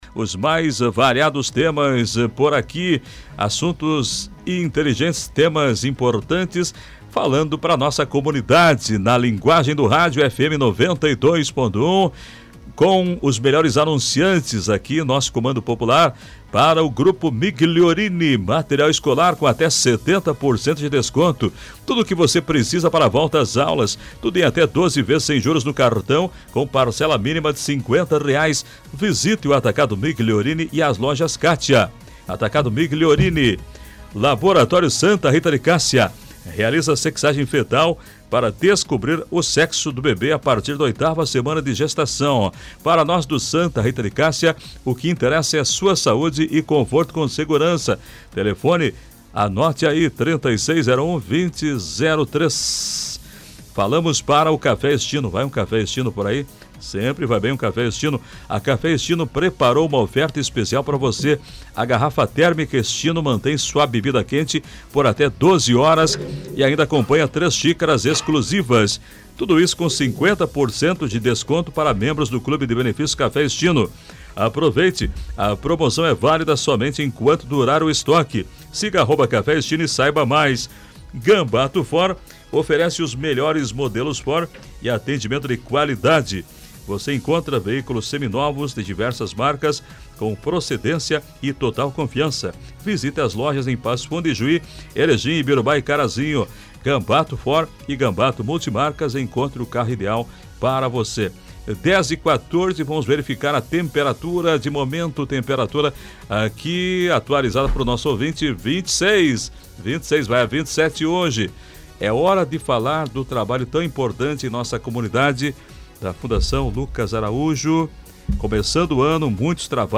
Entrevista: Fundação Beneficente Lucas Araújo, rumo aos 100 anos com formação e acolhida
O trabalho da Fundação Beneficente Lucas Araújo foi destaque na programa Comando Popular, da Rádio Planalto News (92.1) nesta quinta-feira, 29.